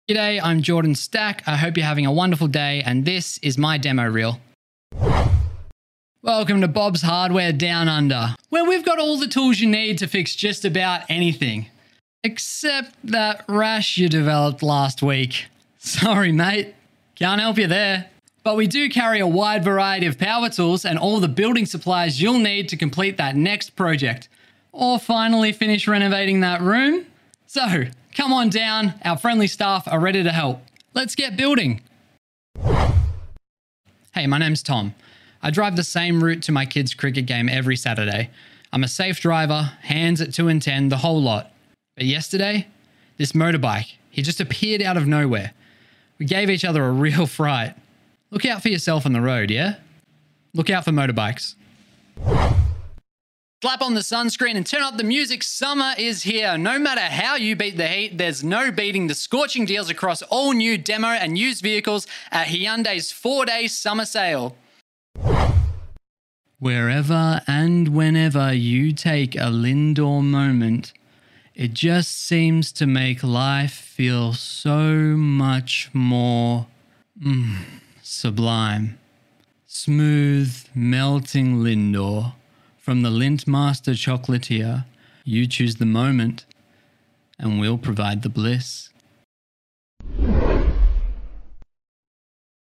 Gender: Male
Voiceover Demo